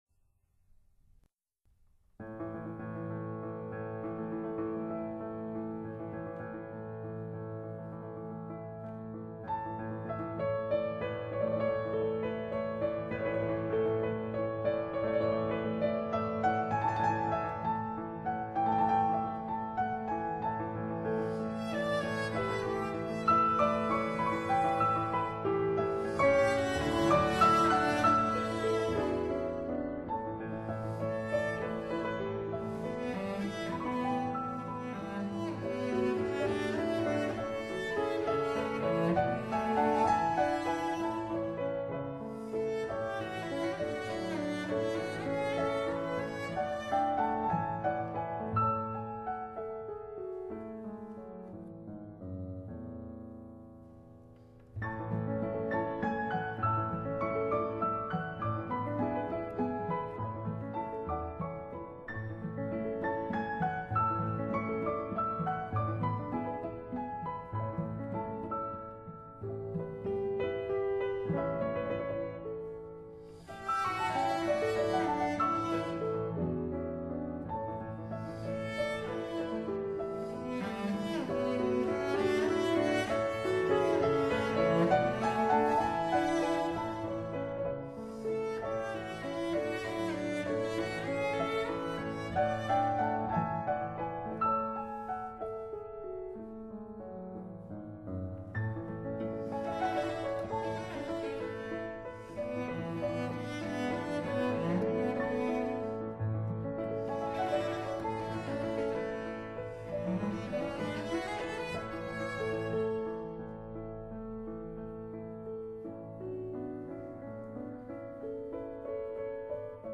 para cello y piano